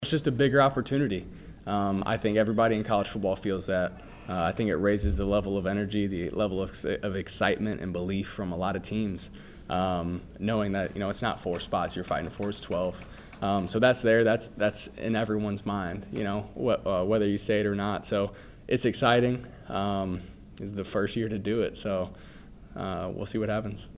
Mizzou player cuts from SEC Media Days.